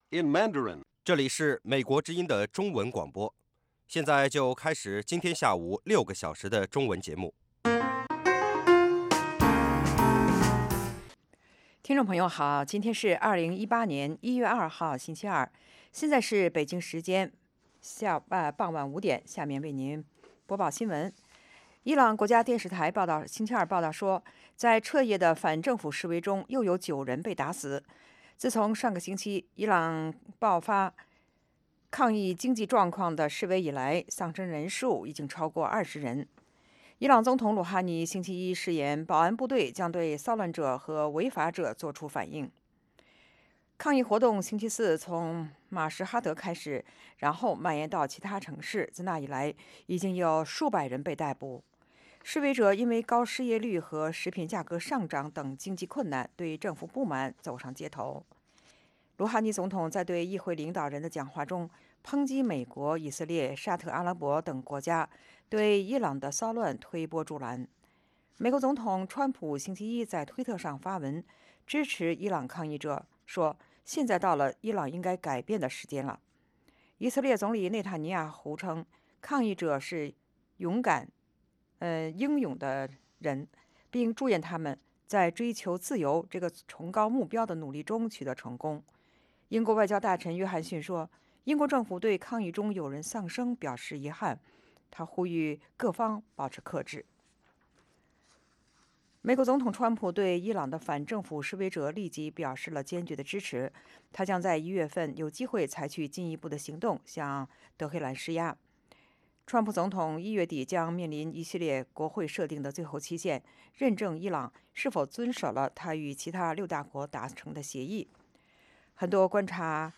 北京时间下午5-6点广播节目。广播内容包括国际新闻，美语训练班(学个词， 美国习惯用语，美语怎么说，英语三级跳， 礼节美语以及体育美语)，以及《时事大家谈》(重播)